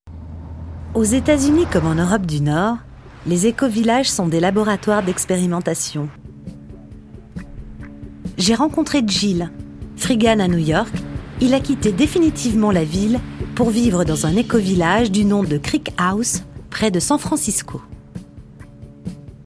Voix off
Documentaire journalistique